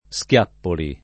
[ S k L# ppoli ]